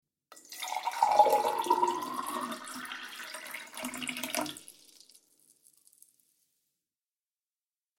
دانلود آهنگ آب 68 از افکت صوتی طبیعت و محیط
جلوه های صوتی
دانلود صدای آب 68 از ساعد نیوز با لینک مستقیم و کیفیت بالا